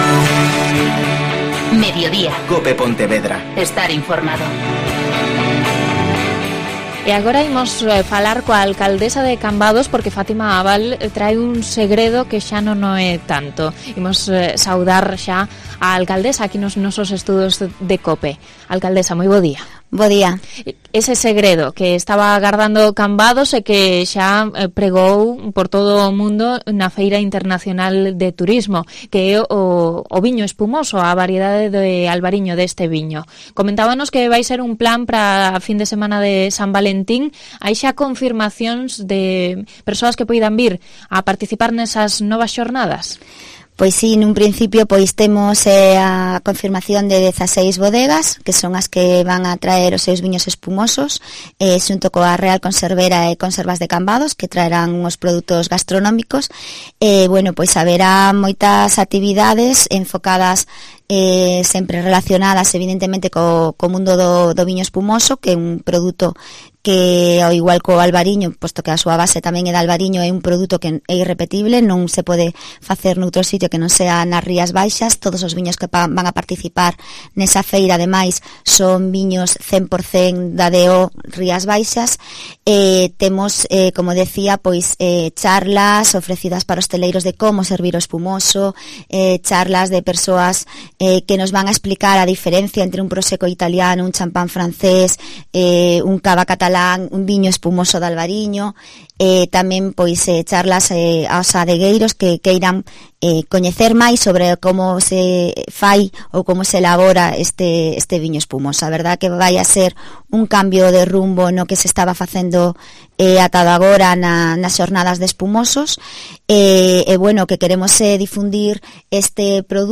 Entrevista a Fátima Abal, alcaldesa de Cambados